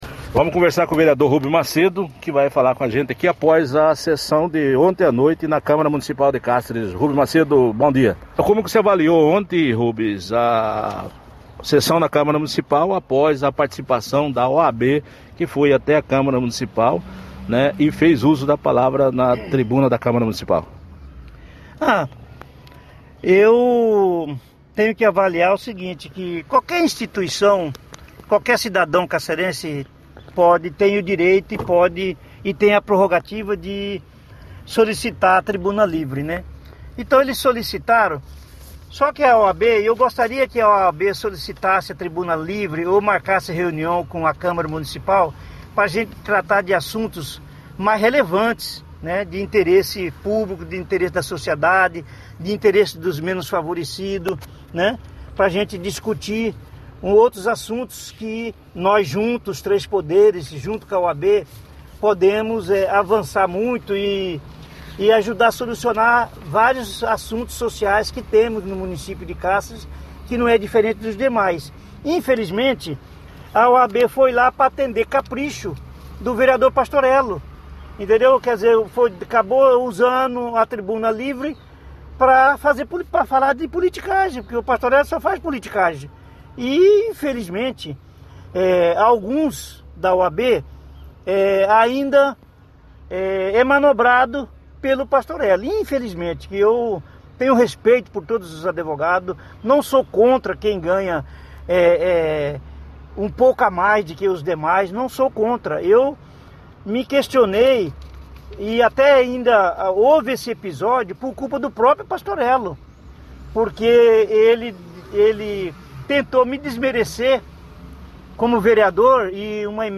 Após a sessão de ontem (13), da Câmara Municipal de Cáceres, o vereador Rubens Macedo deu uma entrevista ao Cáceres News.